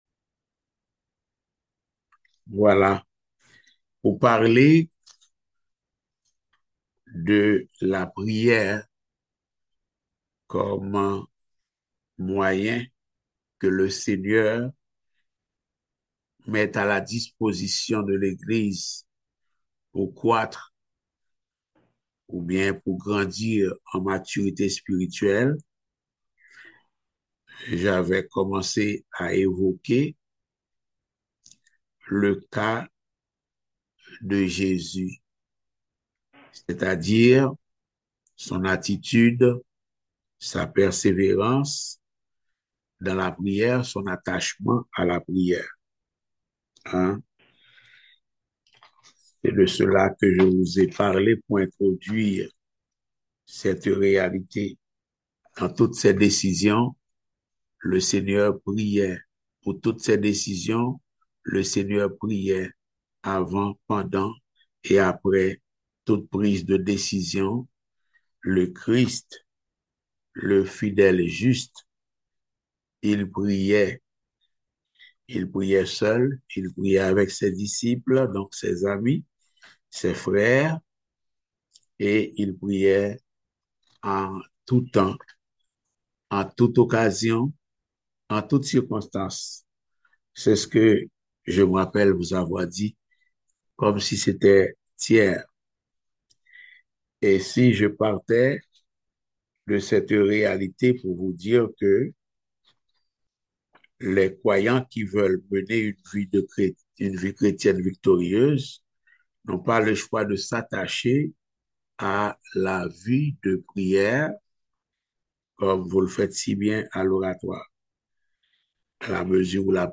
Type De Service: Études Bibliques